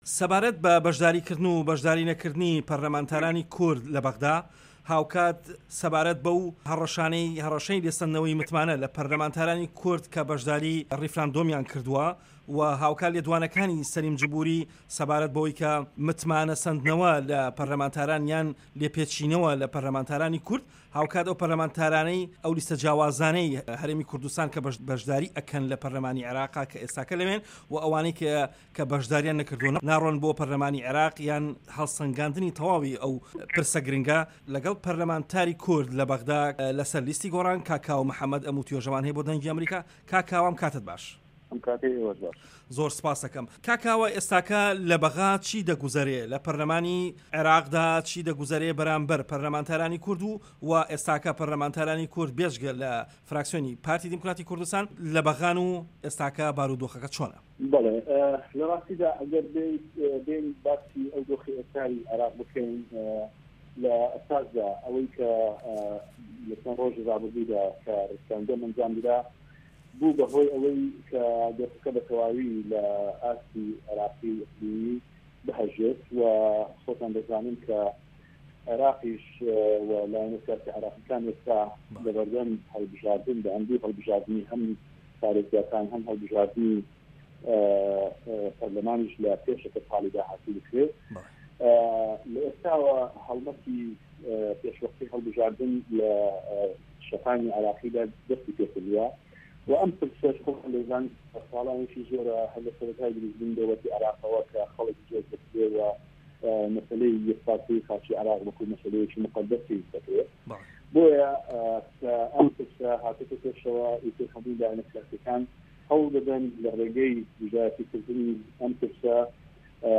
Interview with Kawa Mohammad